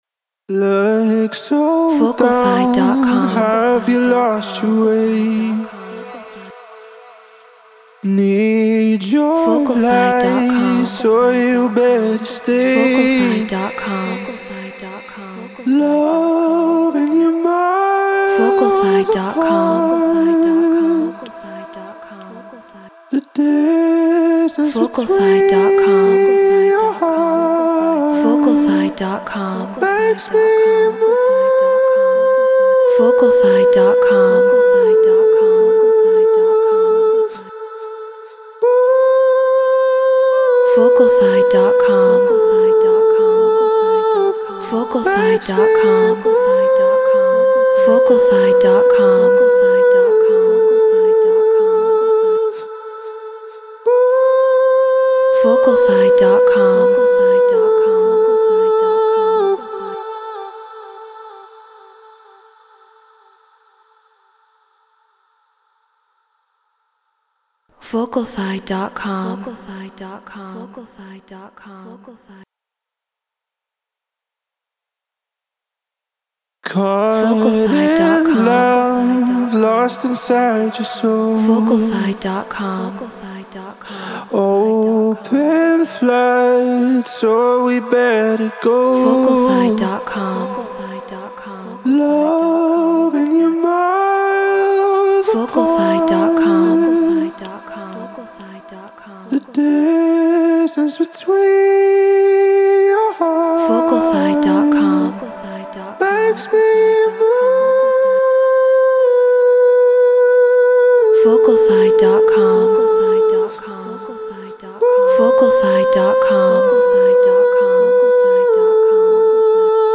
Stutter House 126 BPM Amin
Shure SM7B Apollo Twin X Logic Pro Treated Room